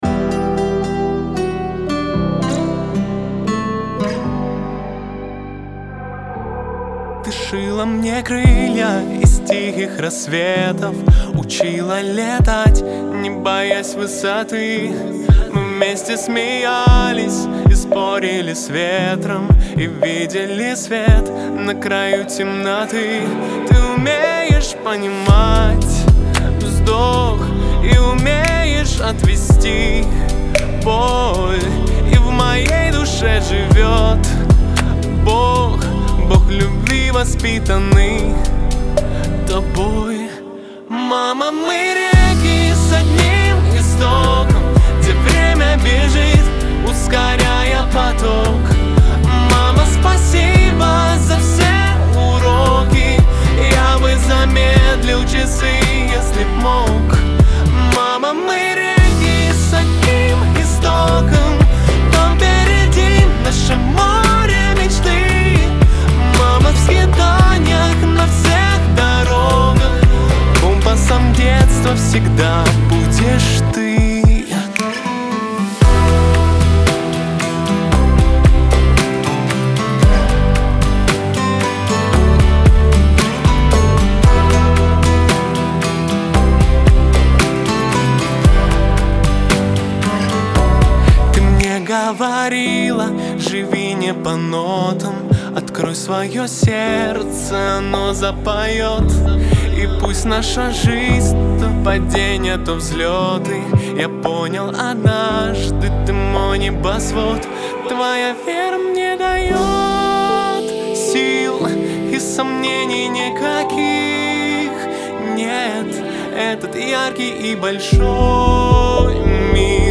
Лирика , pop